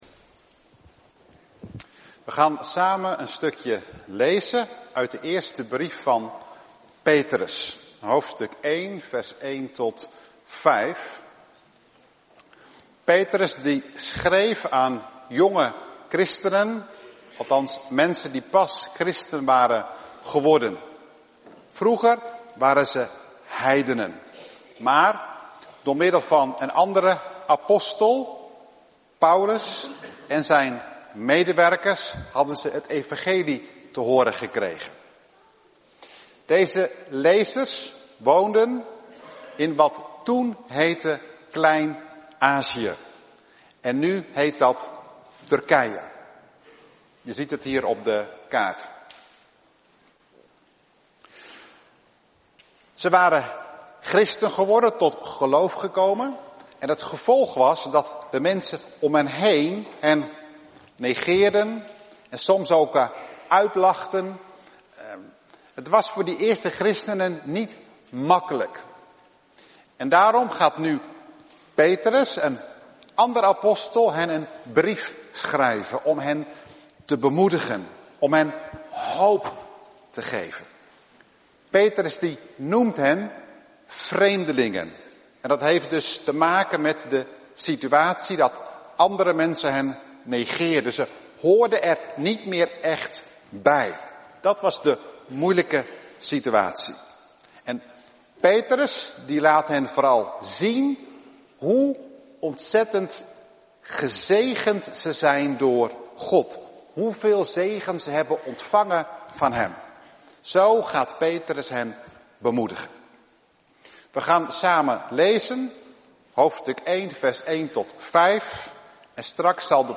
Bekijk verdere details en beluister de preek